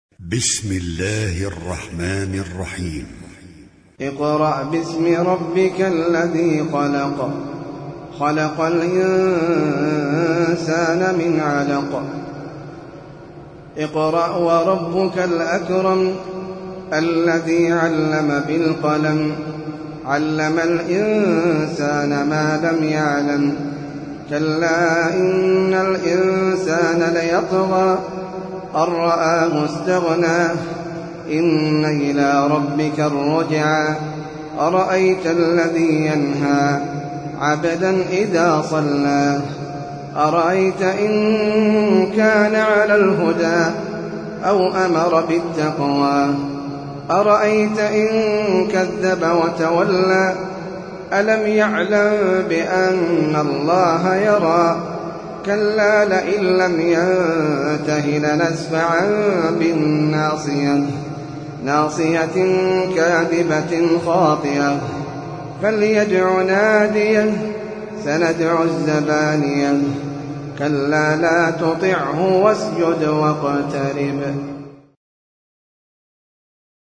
سورة العلق - المصحف المرتل (برواية حفص عن عاصم)
جودة عالية